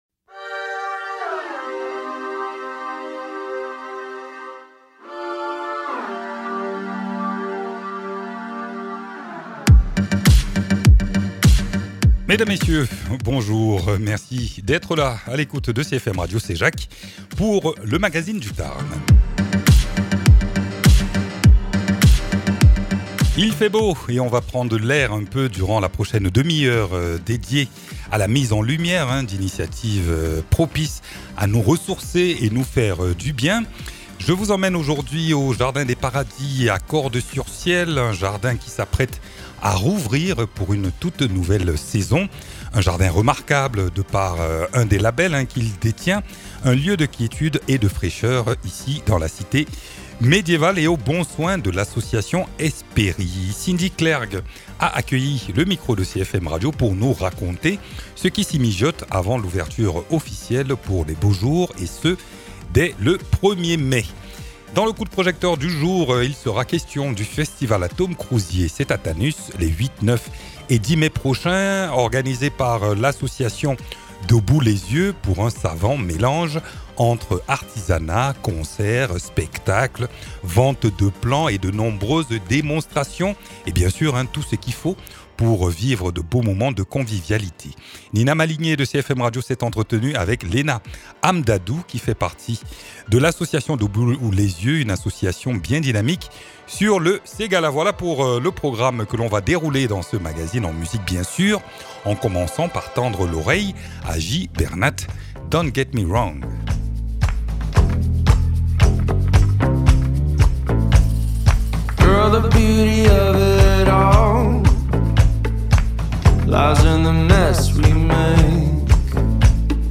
Nous y sommes allés en reportage pour s’enquérir de cette ouverture prochaine et de ce qui s’y déroulera. Et puis dans le focus de la rédaction il est question du festival Atôme Crouzié à Tanus qui allie artisanat, musique, spectacle, vente de plants...